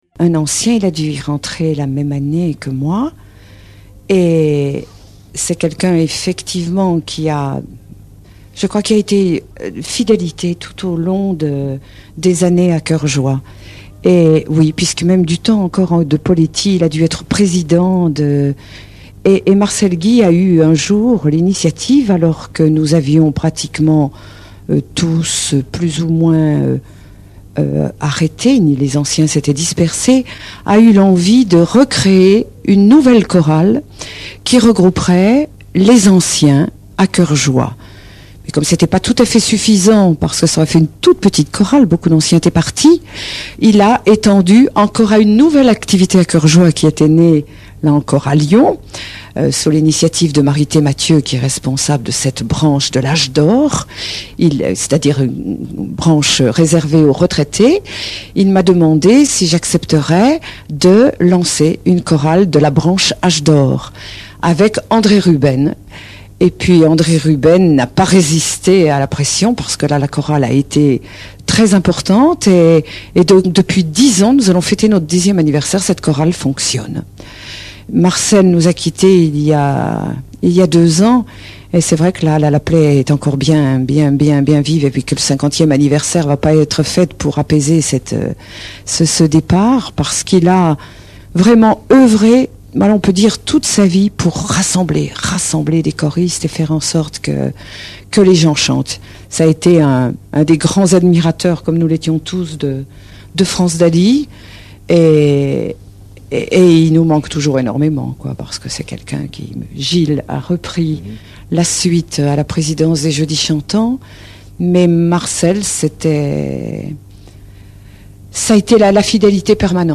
Interview Radio